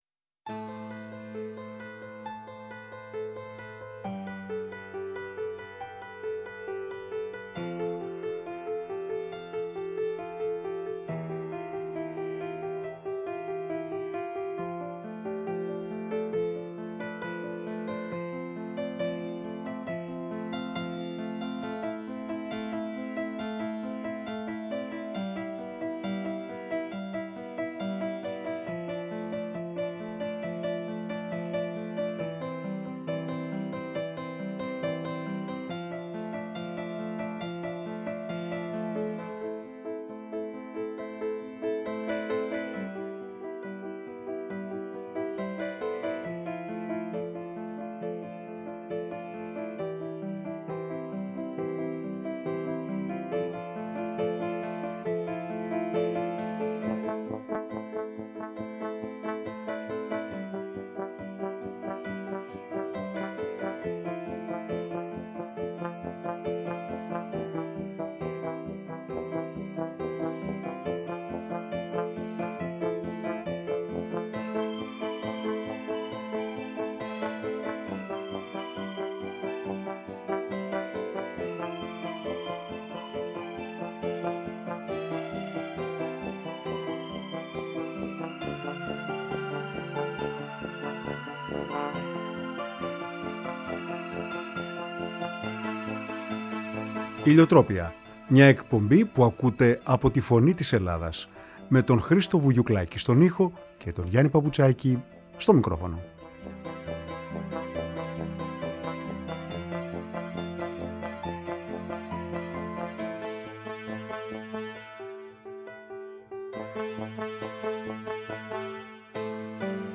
όπου διάβασε αγαπημένα της ποιήματα του Ελύτη, του Καβάφη, του Αναγνωστάκη και του Καββαδία.